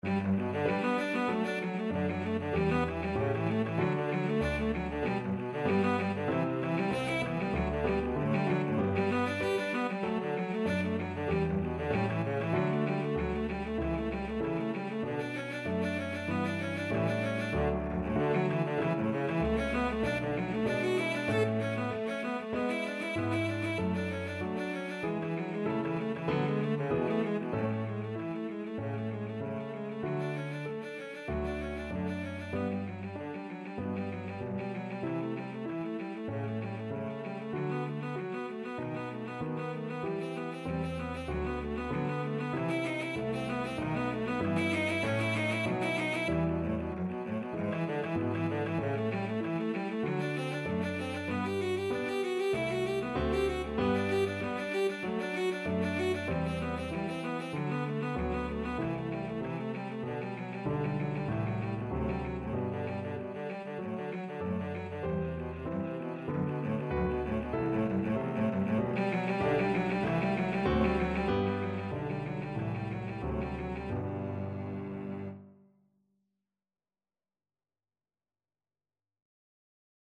Cello
G major (Sounding Pitch) (View more G major Music for Cello )
D3-A5
4/4 (View more 4/4 Music)
Classical (View more Classical Cello Music)
corelli_op5_1_allegro_VLC.mp3